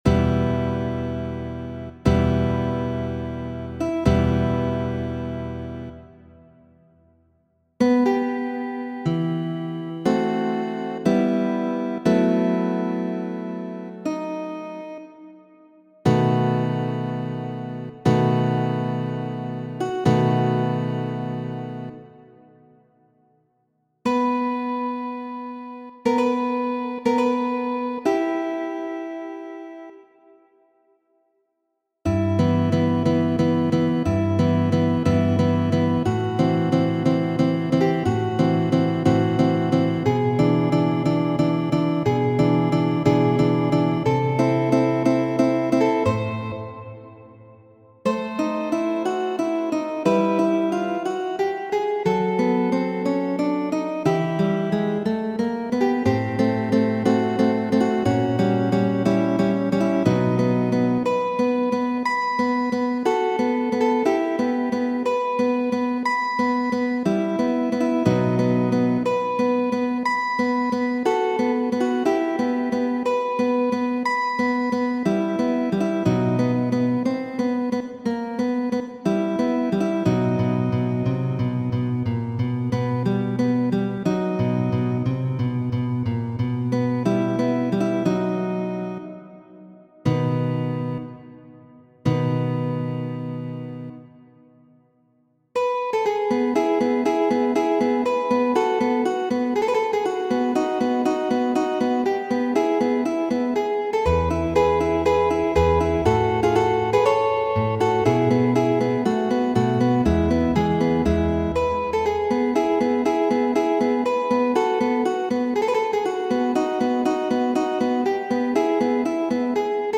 Variaĵoj pri temo de opero La Magia Fluto, de Mozart, verkita de Fernando Sor (samtempulo de Fransisko de Goja) kaj enkumputiligita de mi mem.